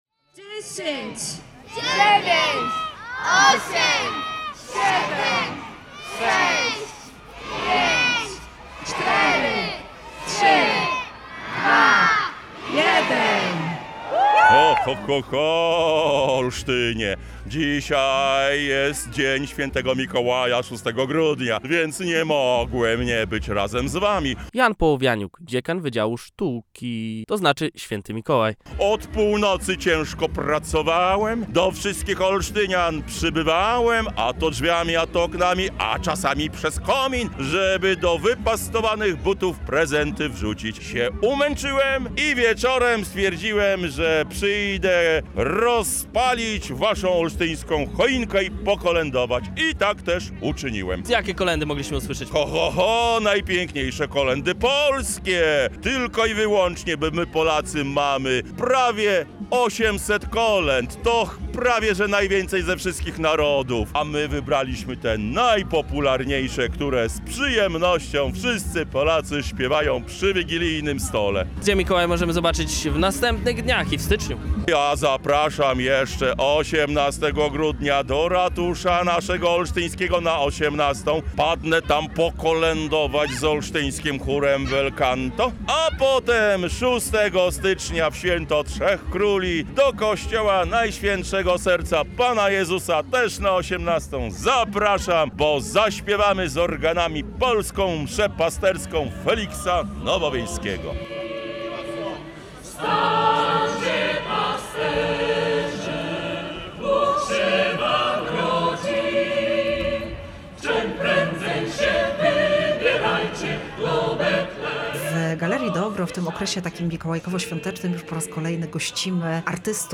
Odpalanie-choinki-calosc.mp3